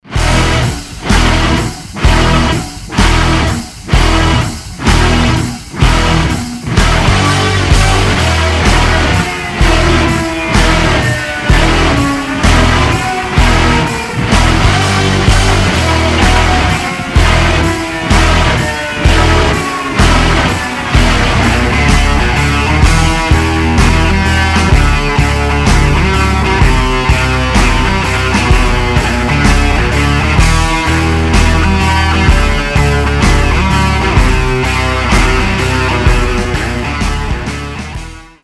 • Качество: 320, Stereo
без слов
инструментальные
электрогитара
Industrial metal